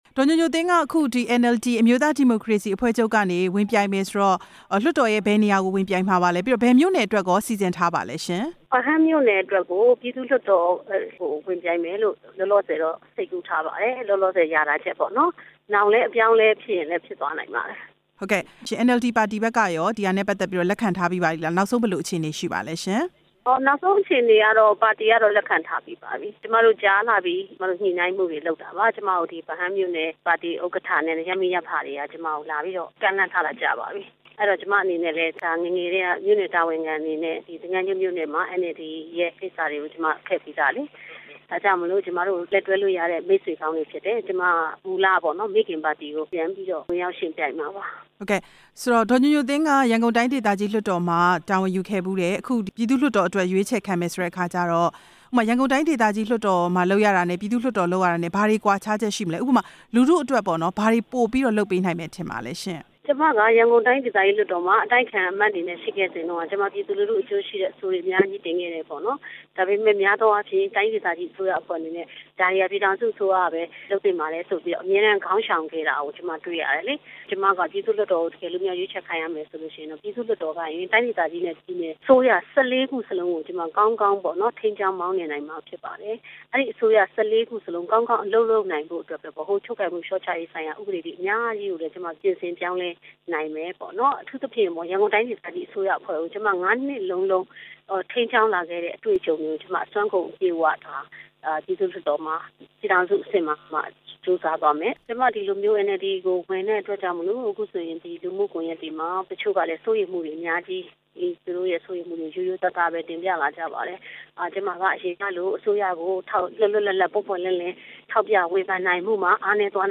ဒေါက်တာဒေါ်ညိုညိုသင်းနဲ့ မေးမြန်းချက်